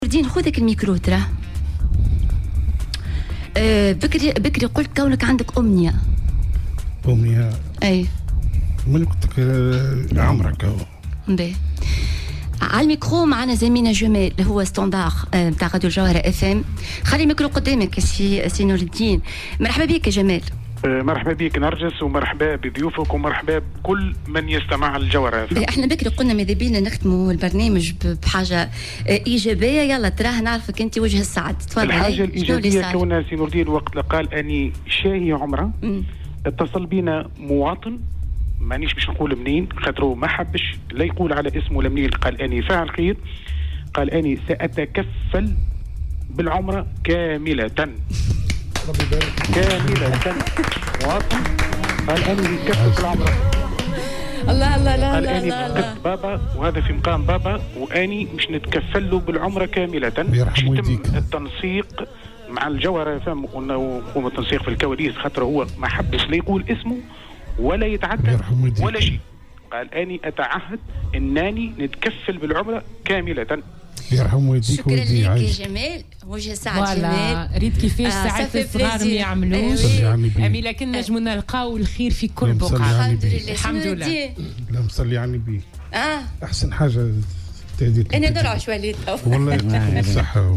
عبّر أحد المقيمين بدار المسنين بسوسة في برنامج "ديالوق" اليوم الاثنين على "الجوهرة أف أم" عن رغبته في القيام بعمرة.